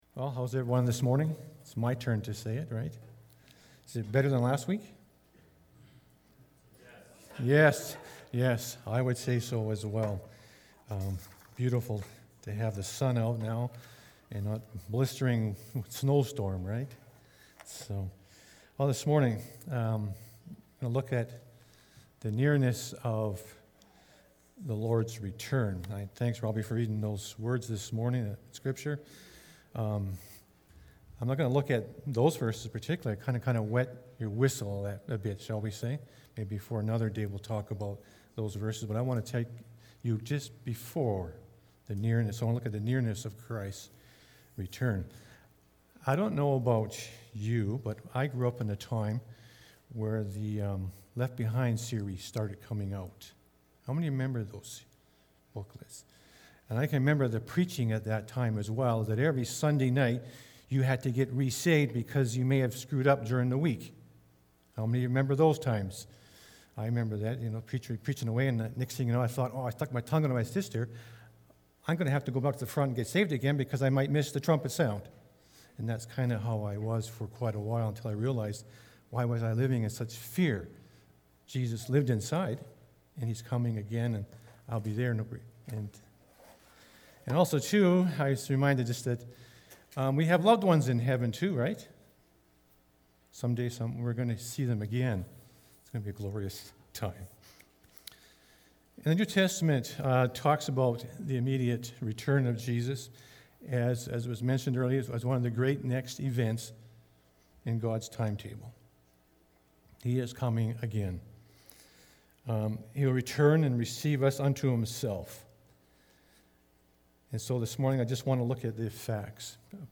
March-7-2021-sermon.mp3